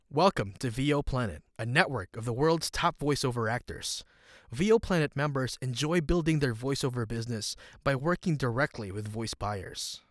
VOPlanet Demo - Raw home studio sample
GenAm English, Southern, RP, Taglish, Cebuano